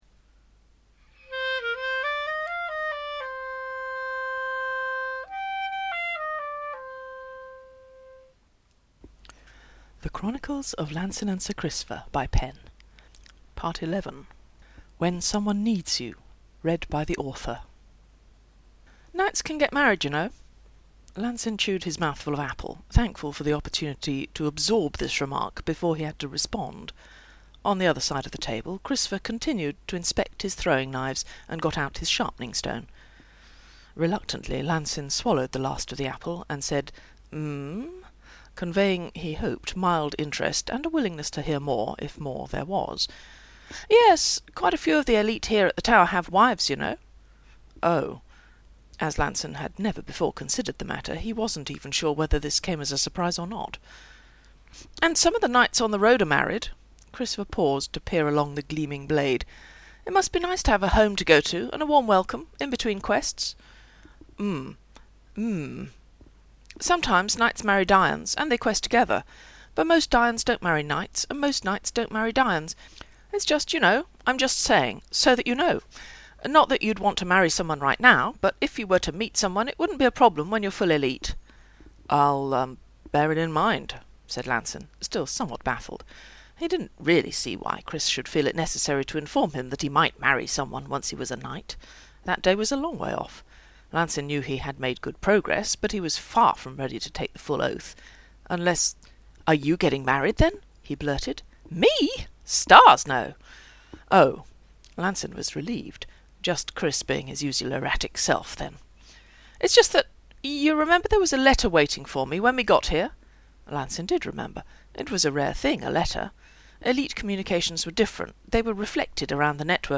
This story is available as podfic (mp3): right-click to download